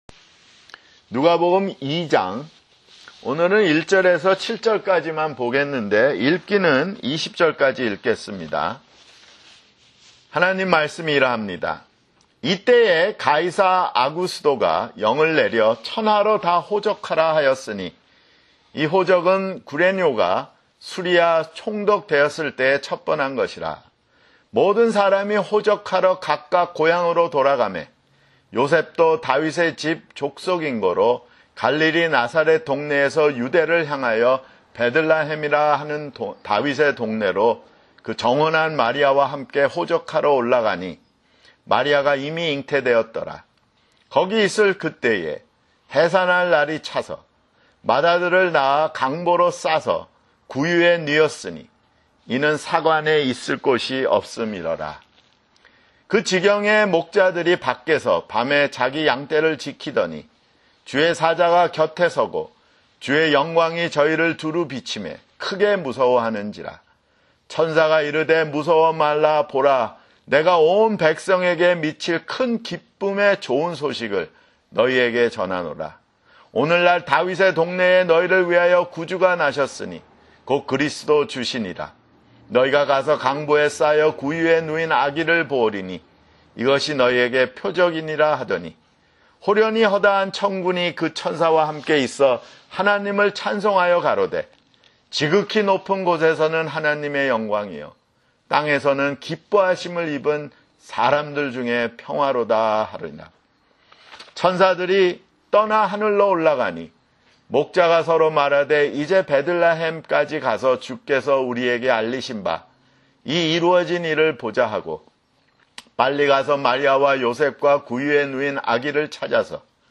[주일설교] 누가복음 (14)